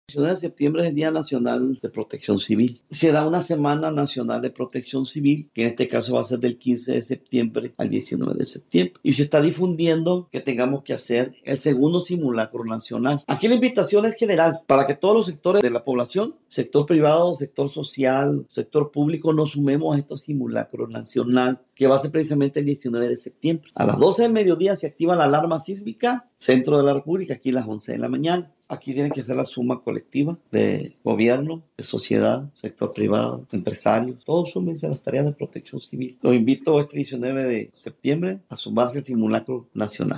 Francisco Cota Márquez – director municipal de Protección Civil